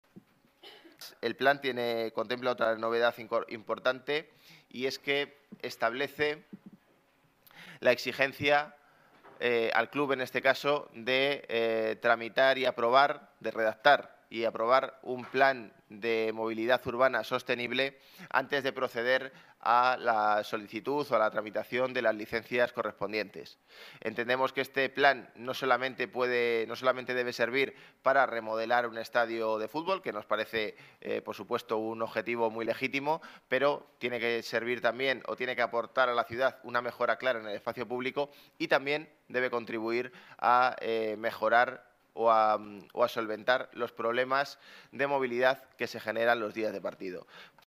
Declaraciones de José Manuel Calvo sobre el plan movilidad sostenible que requiere la remodelación del estadio